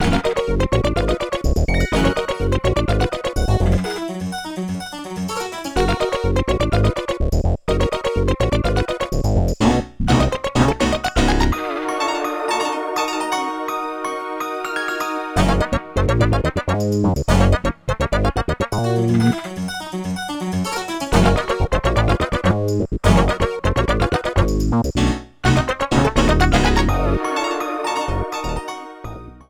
applied fade-out to last two seconds
Fair use music sample